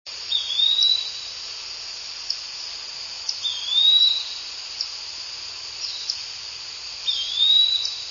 Lower Coppermines Trail near Delaware River, 9/9/02, 12:00 p.m. (kb32) dense tree canopy, open forest just above mine.  Single ascending whistle "tuweeE" with chipmunks
flycatcher_yellow-bellied_tuwee_562.wav